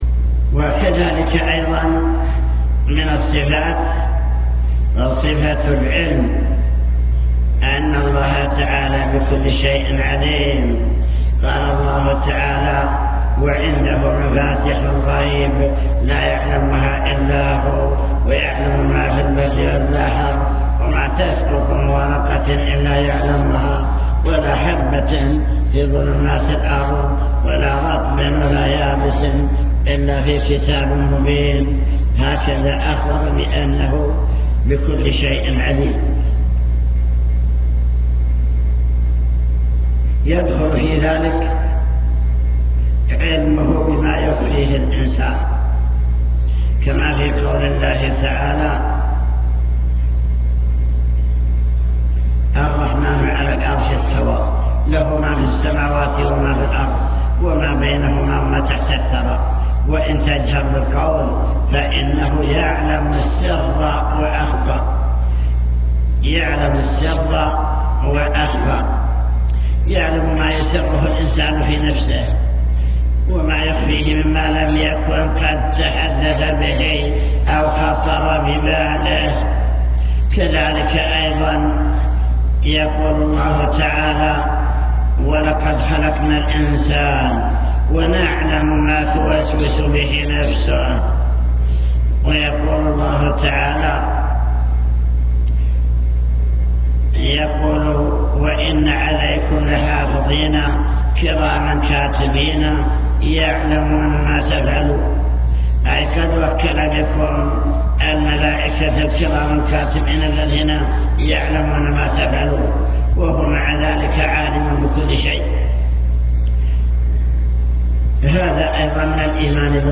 المكتبة الصوتية  تسجيلات - محاضرات ودروس  محاضرة الإيمان باليوم الآخر